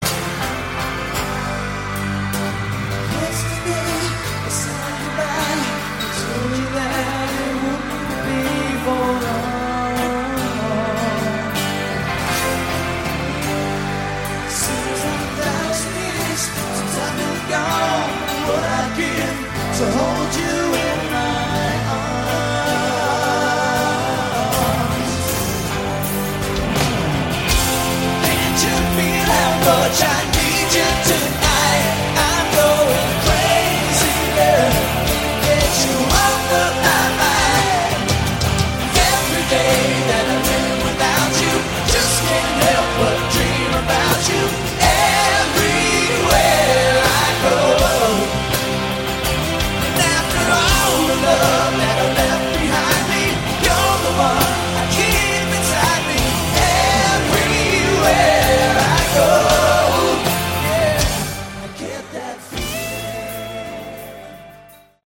Category: AOR
lead vocals, guitar
lead vocals, bass
vocals, keyboards
drums
live